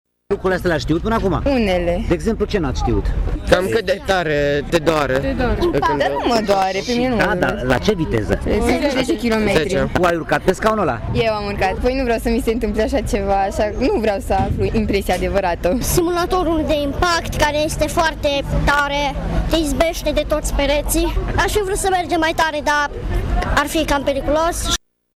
Polițiștii au expus în centrul municipiului Tg.Mureș autospecială radar şi motocicleta serviciului rutier.